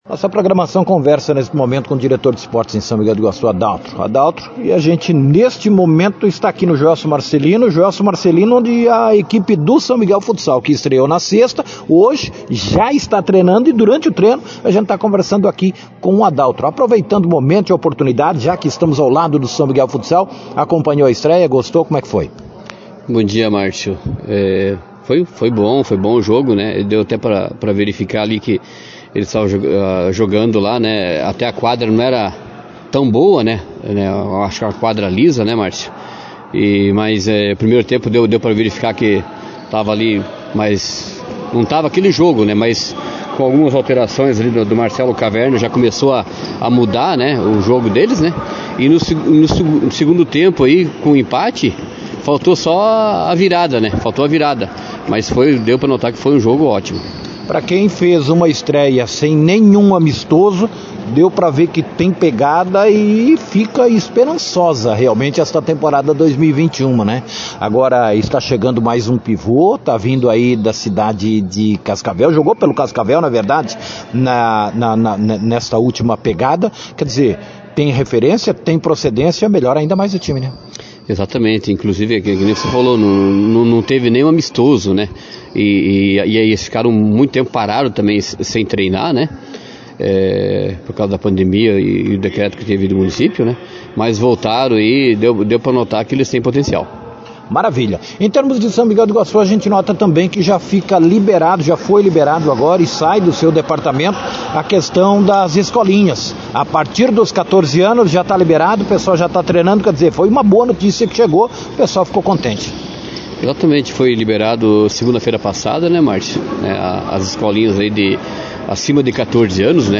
Em entrevista à Rádio Jornal